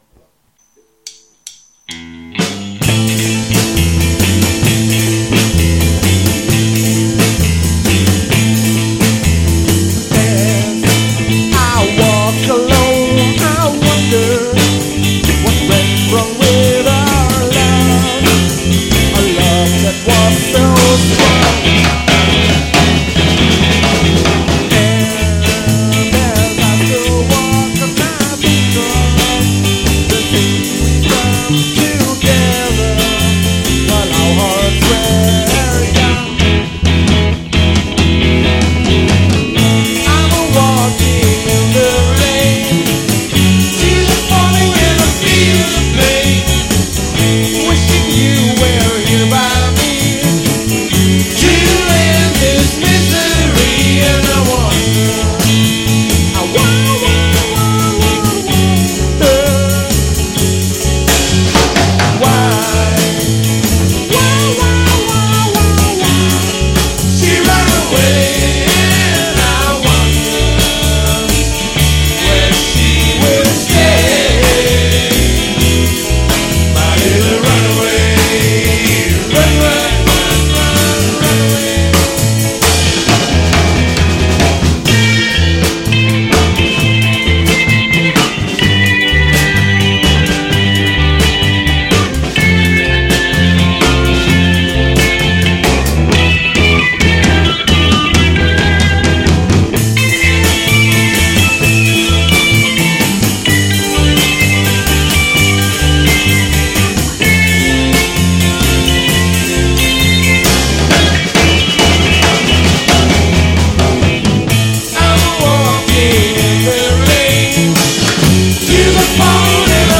Rock & Roll Band!
Memories of Rock & Roll, Soul, Rhythm Blues.
Ehrliche, handgemachte Rock- Musik
Bass
Drums